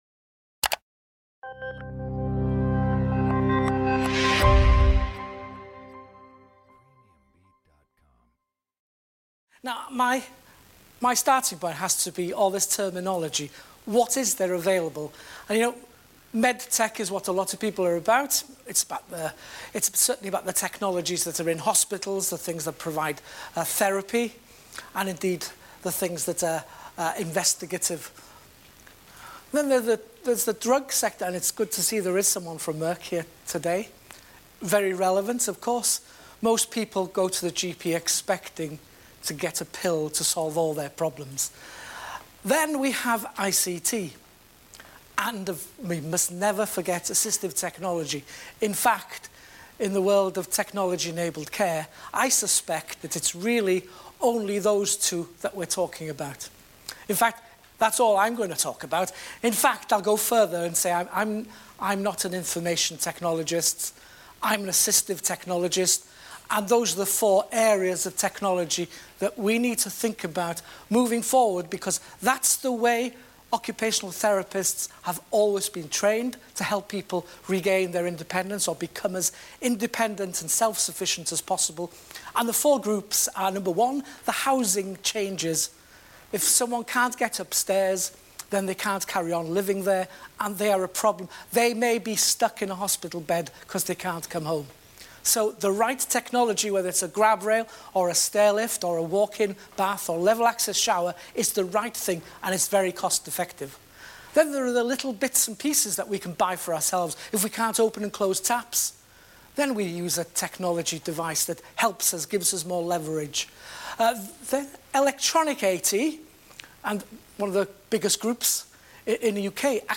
Recorded at Aintree Racecourse - 13/09/2016